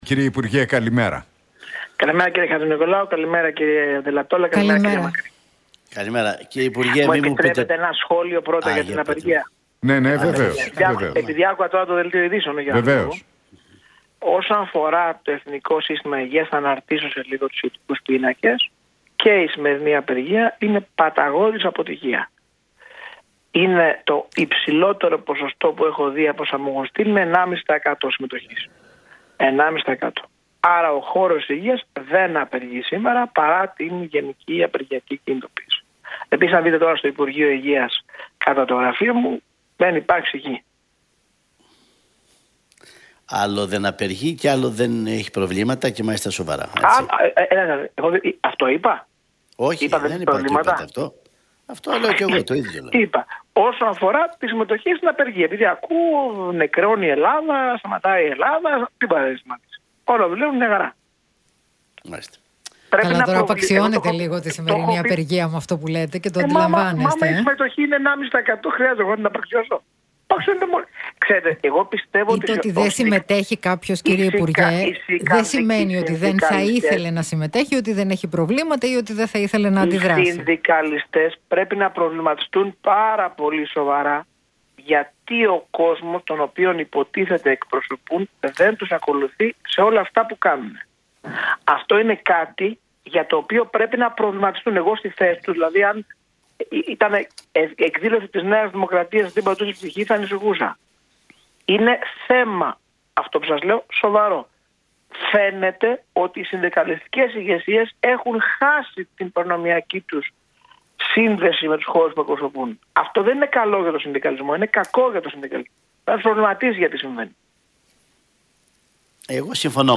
Τη συμμετοχή των εργαζομένων της Υγείας στη σημερινή απεργία σχολίασε μεταξύ άλλων ο Άδωνις Γεωργιάδης μιλώντας στον Realfm 97,8 και στους Νίκο Χατζηνικολάου,